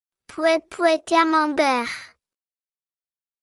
\Pwèt Pwèt-Cam-mom-Bear\